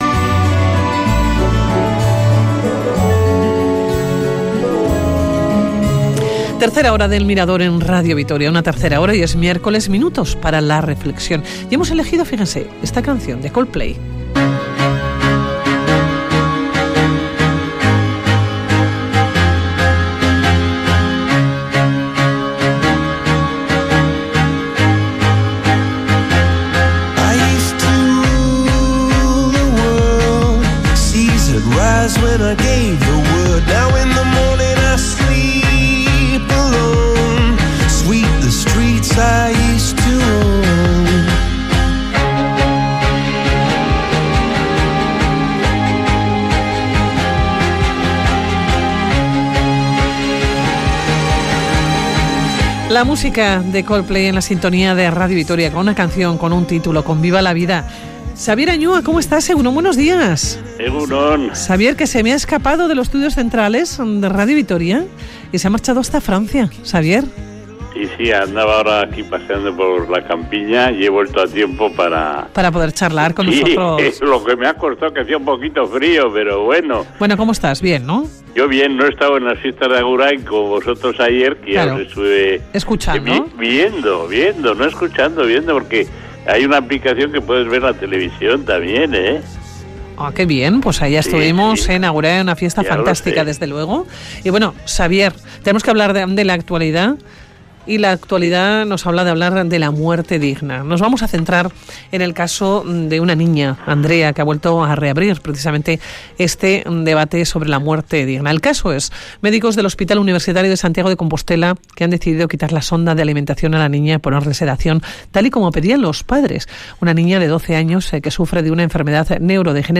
Eutanasia y concierto económico, temas de la tertulia de sabios de hoy